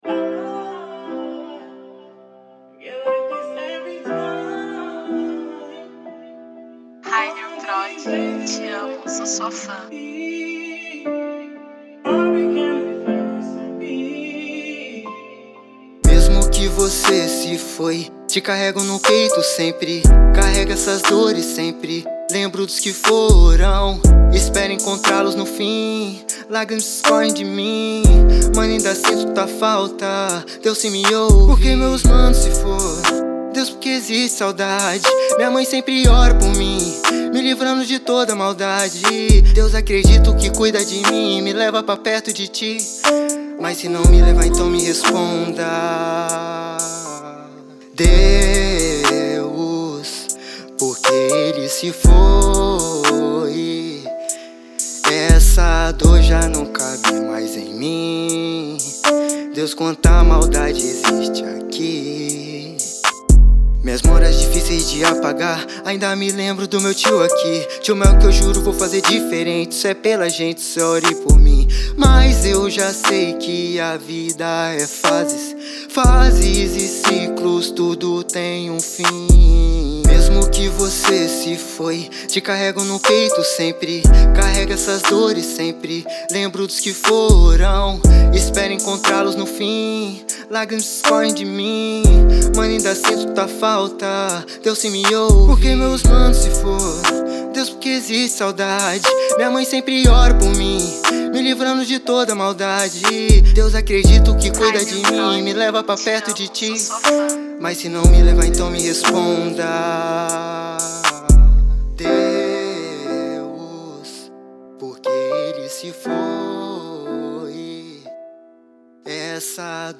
EstiloTrap